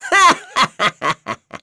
Neraxis-Vox-Laugh.wav